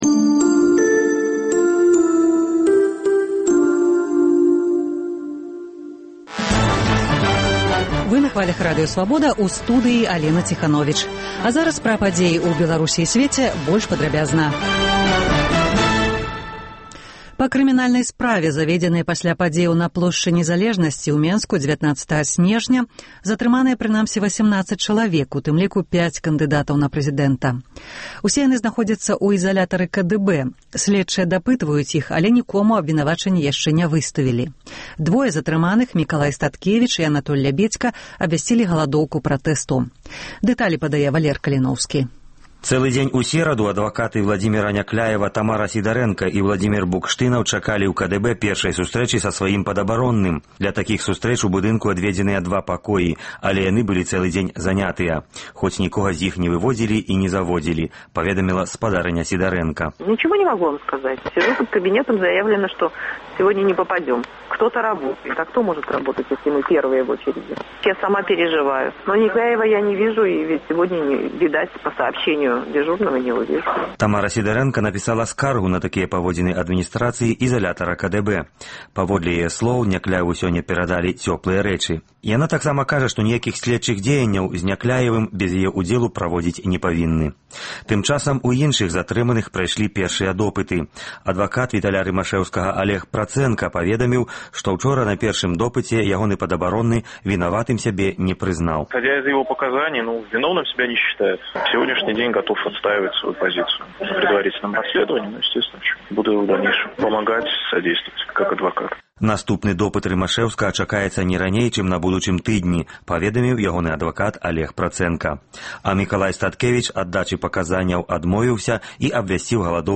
Паведамленьні нашых карэспандэнтаў, званкі слухачоў, апытаньні ў гарадах і мястэчках Беларусі. Праскі акцэнт: Як рэагуе беларускае грамадзтва на выбары і падзеі 19 сьнежня ў Менску? Які быў плян апазыцыйных лідэраў наконт Плошчы?